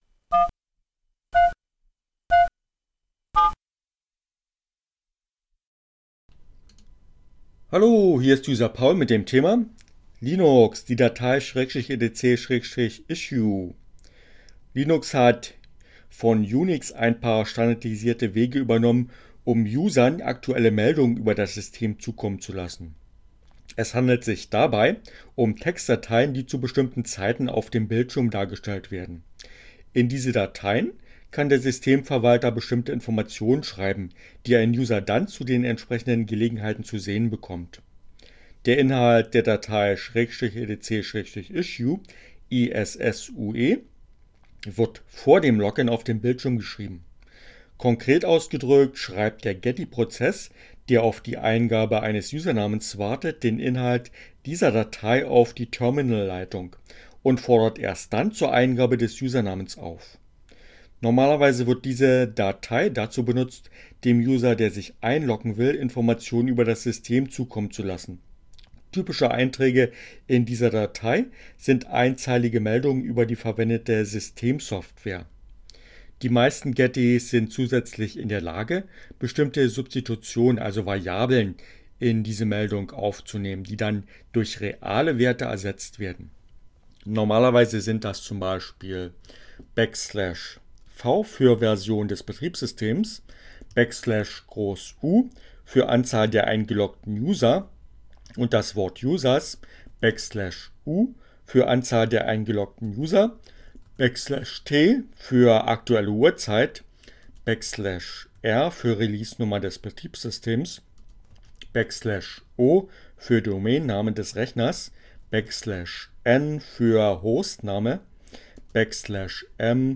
Tags: CC by, Linux, Neueinsteiger, ohne Musik, screencast, Linux Mint